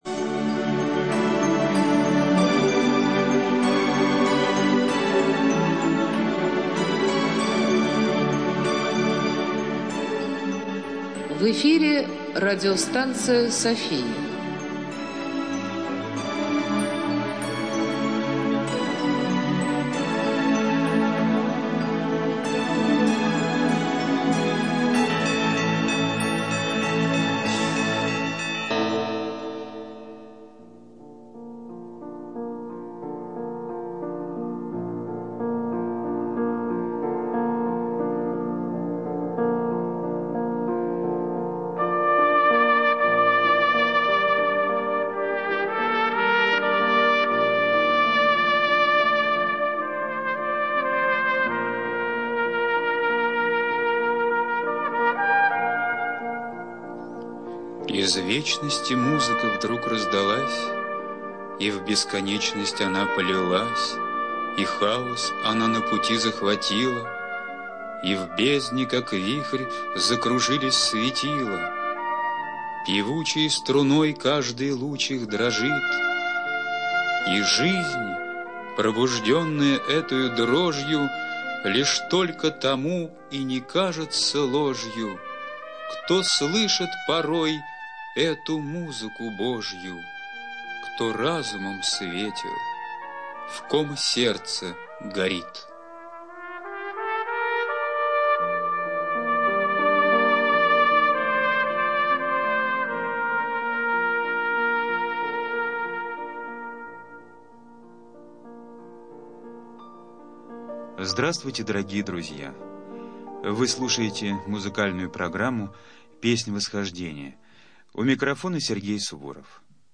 ЖанрРадиопрограммы